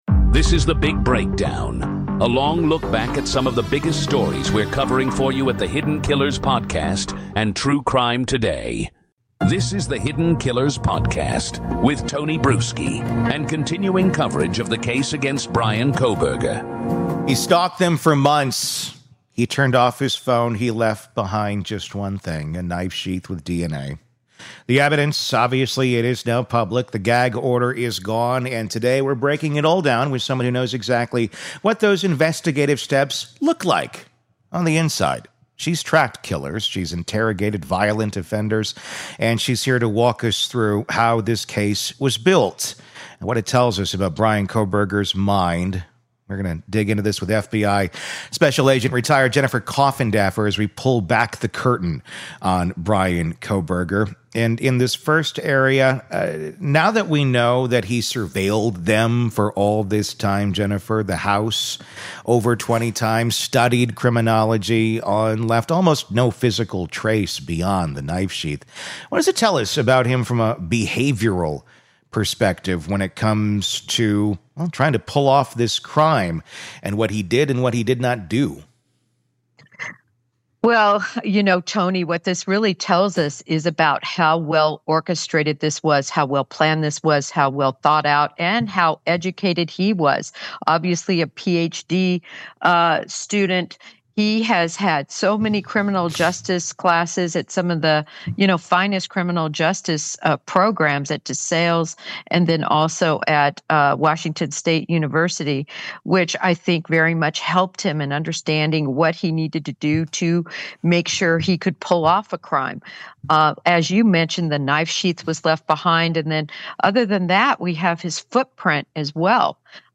We also break down the digital footprints revealed in the documents, how law enforcement tracked him across states, and why the sheath DNA may have been more intentional than we think. This is a no-fluff, fact-driven, and deeply informed discussion you won’t hear anywhere else.